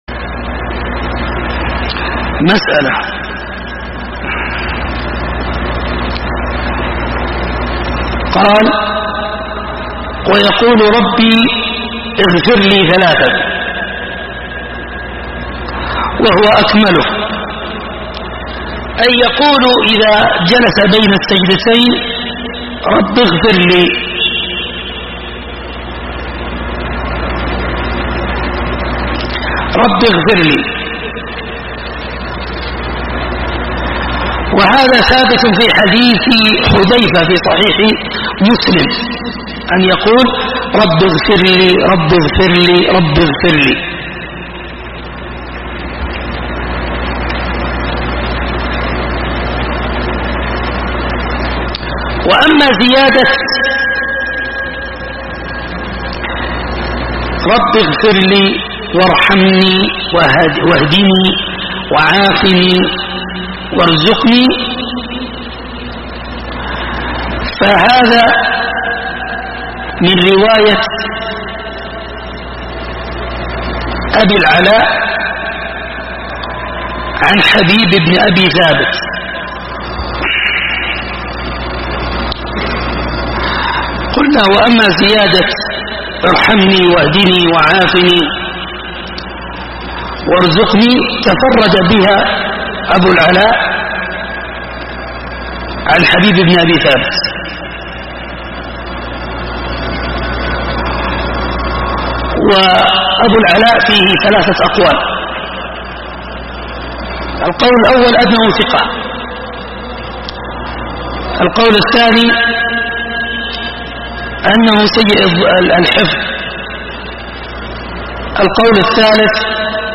دروس وسلاسل